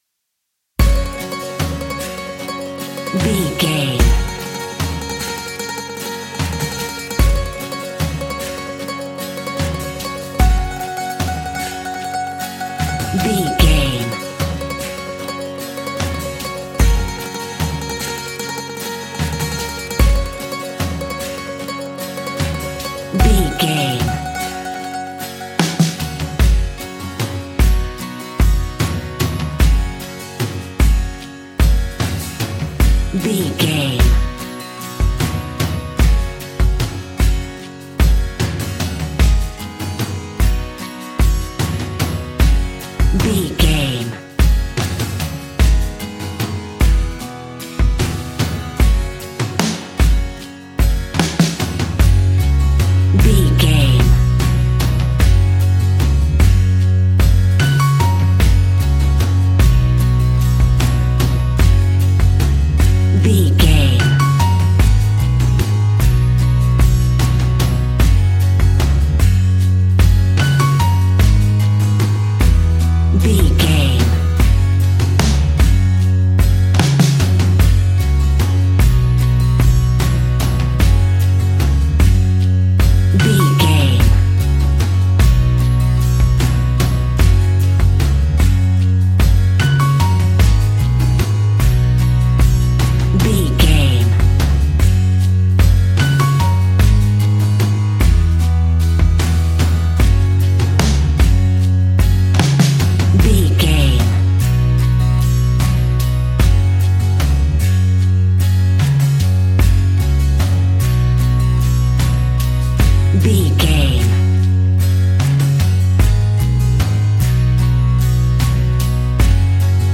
Uplifting
Ionian/Major
G♭
acoustic guitar
mandolin
ukulele
lapsteel
drums
double bass
accordion